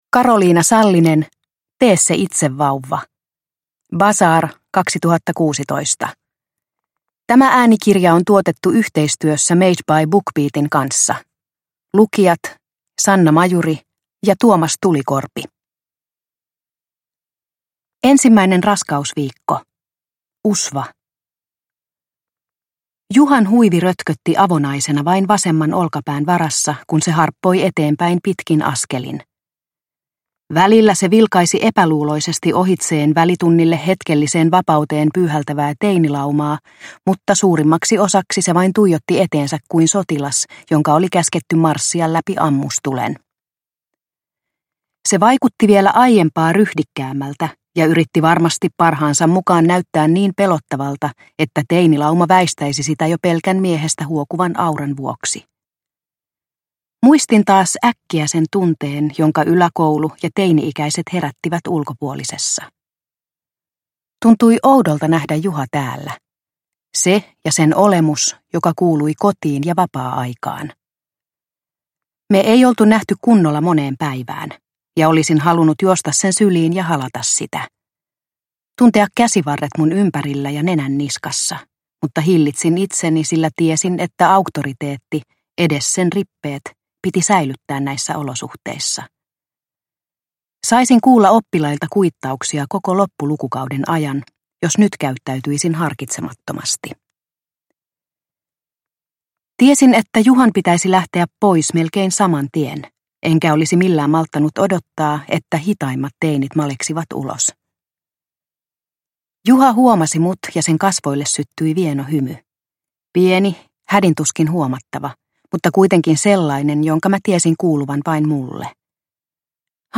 Tee se itse -vauva – Ljudbok